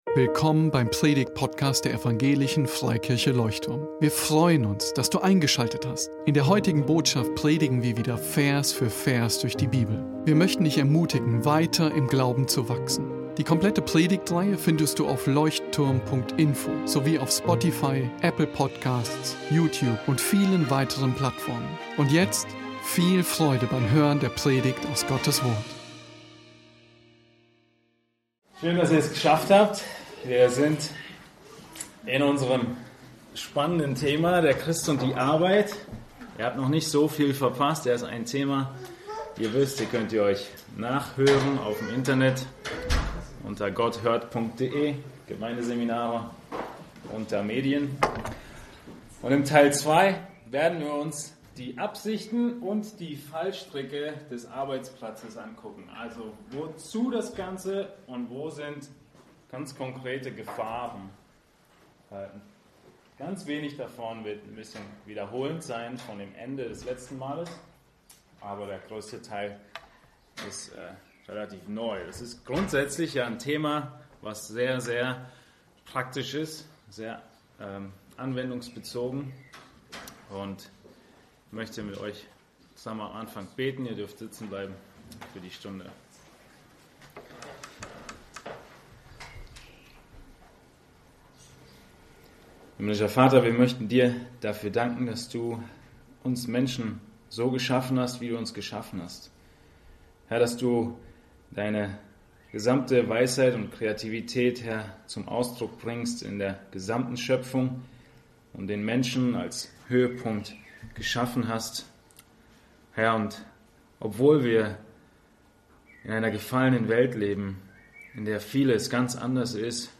Ziele und Fallstricke von Beschäftigung ~ Leuchtturm Predigtpodcast Podcast